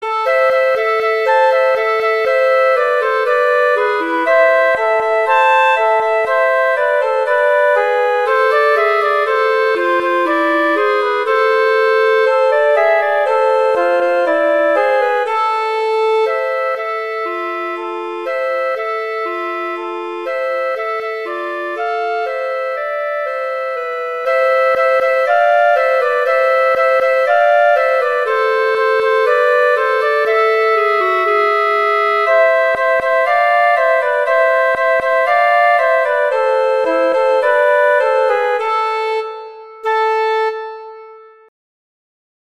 easy duet
Instrumentation: flute & clarinet
arrangements for flute and clarinet